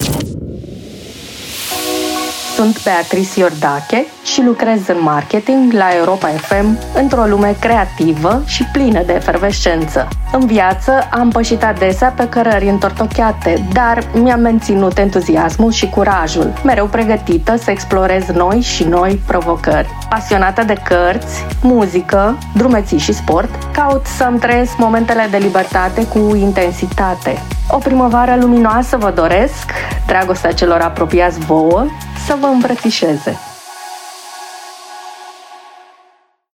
Câteva dintre mesajele colegelor noastre: